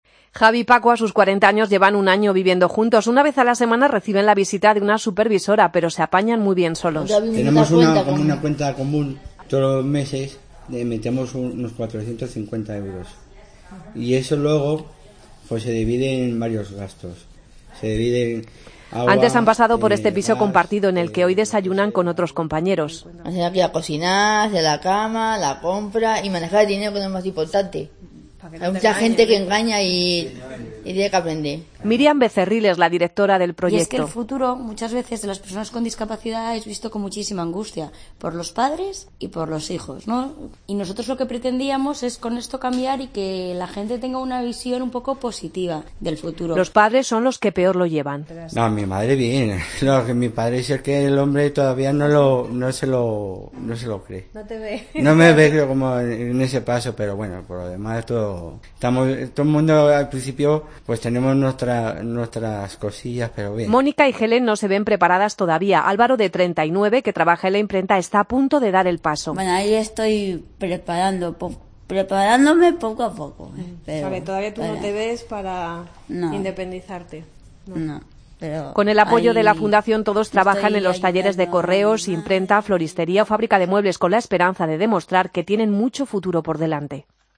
AUDIO: Escucha el reportaje: Los discapacitados intelectuales pueden tener una vida independiente